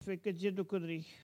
parole, oralité
Collectif atelier de patois
Catégorie Locution